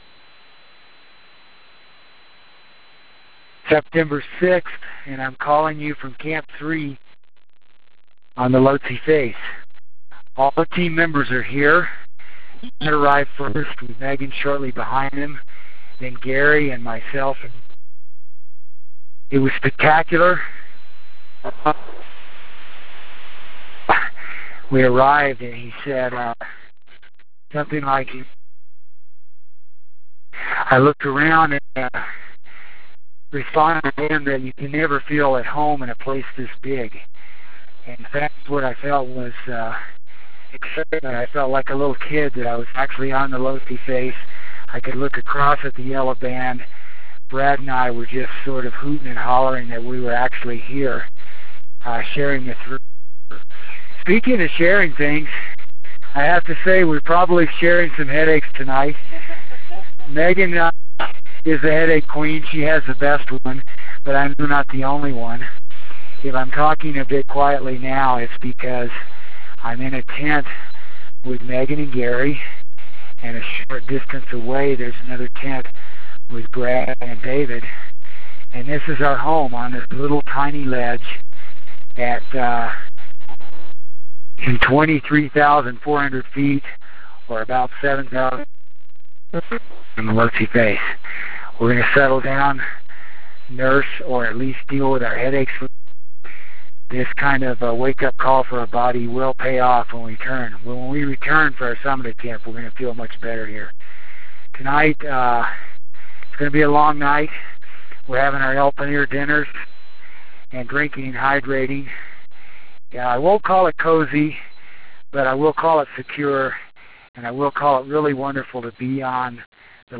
October 6 - Settling in at Camp 3